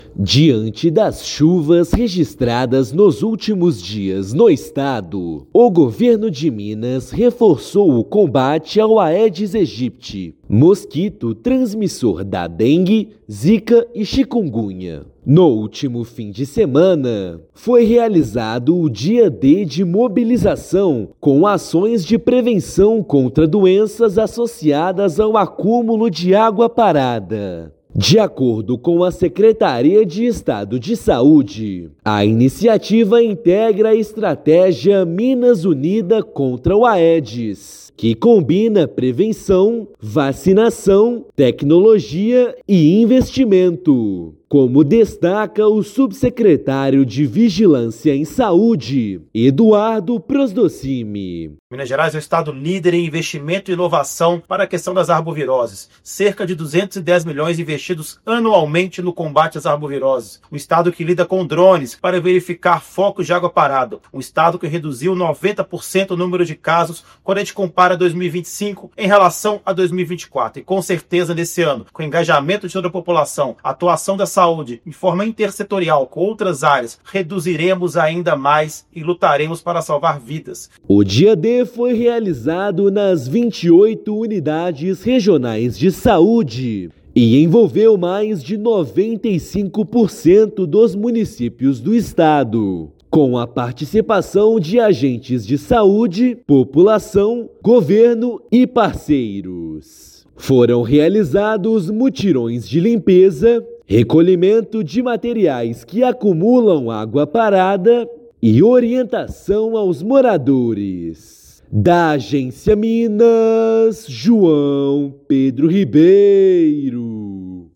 Ações de prevenção e conscientização foram realizadas em pelo menos 814 municípios, para eliminar possíveis criadouros do mosquito. Ouça matéria de rádio.